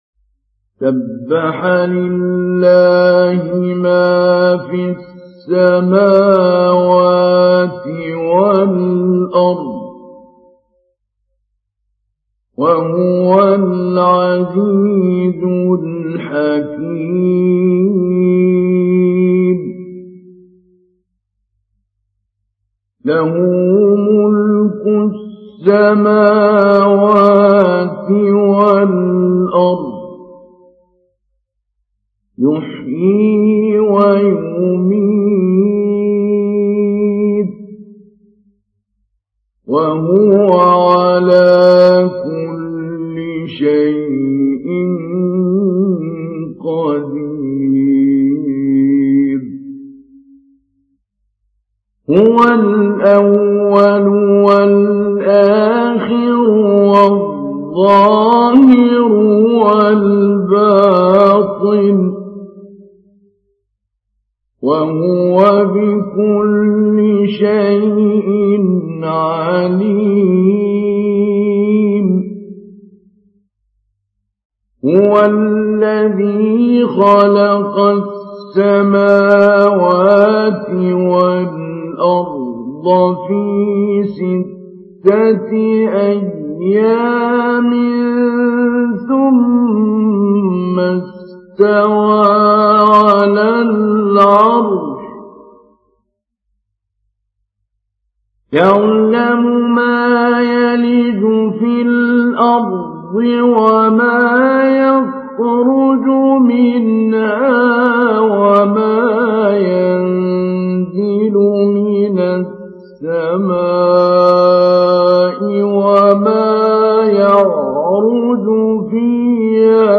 تحميل : 57. سورة الحديد / القارئ محمود علي البنا / القرآن الكريم / موقع يا حسين